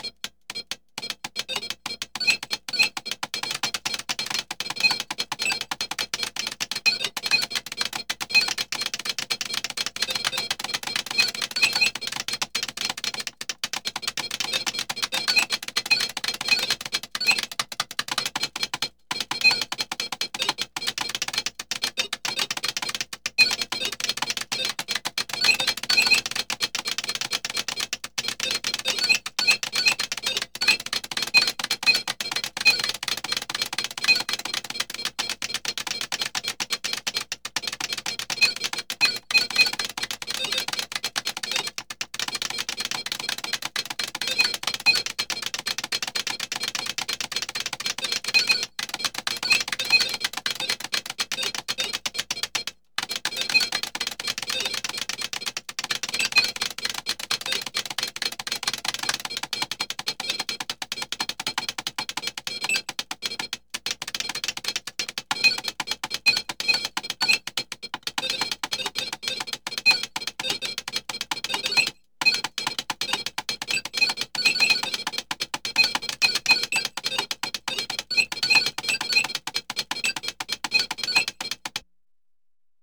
ambience
Computer Video Game - Punching on Keyboard and Beeps 1